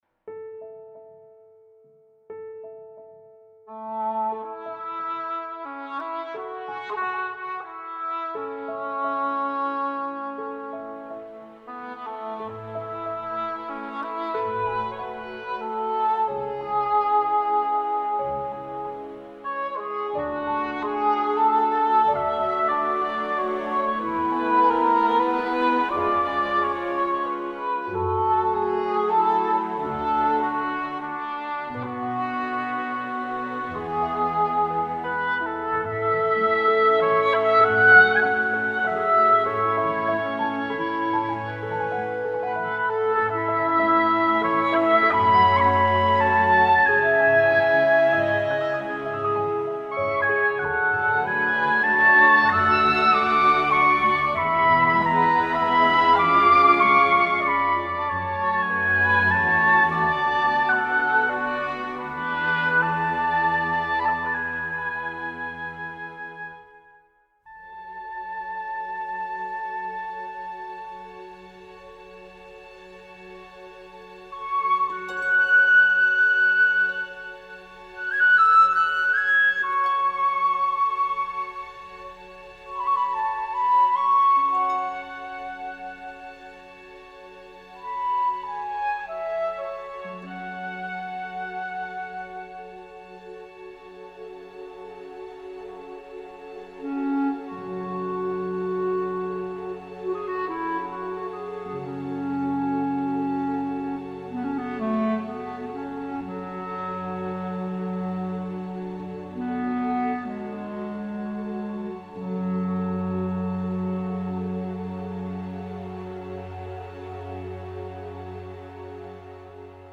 旗舰木管乐器
在Teldex得分舞台上录制，声音自然，圆润，并与其他柏林系列完美融合。
所有乐器都以相同的自然增益水平精心录制，音乐家位于传统管弦乐队的座位位置。